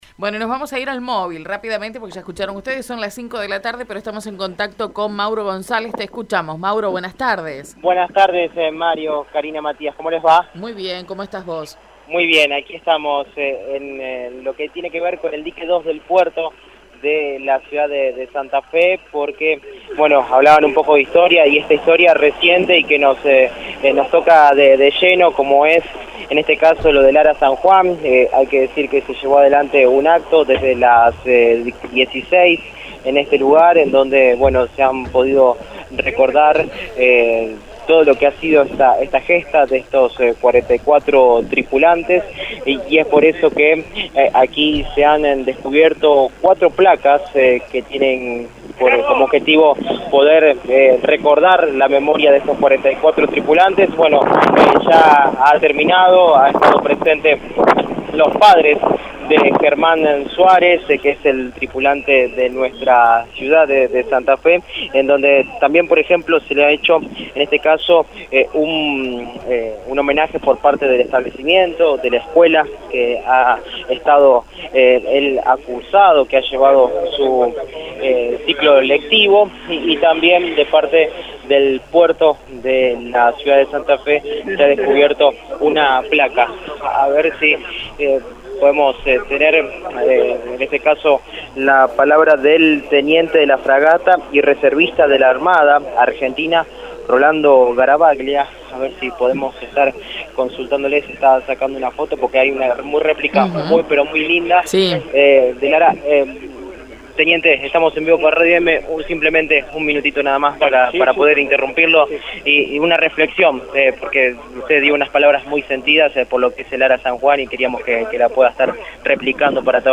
Según relataron al móvil de Radio EME, a la conmemoración asistieron personalidades que llegaron desde Salta, Córdoba, Buenos Aires, entre otros.